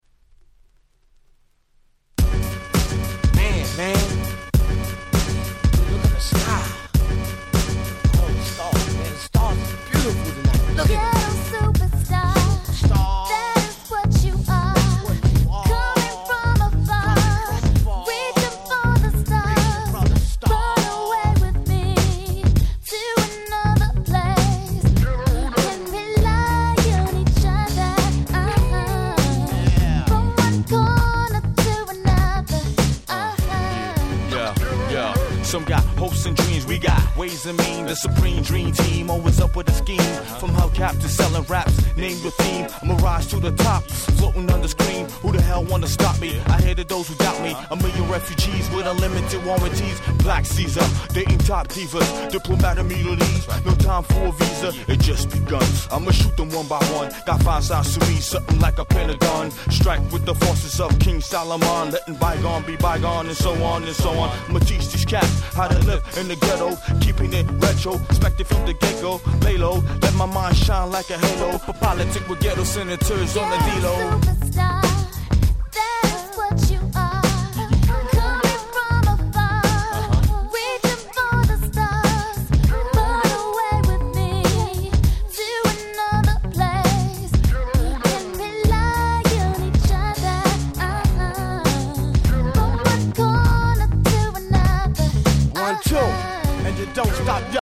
98' Super Hit R&B/Hip Hop !!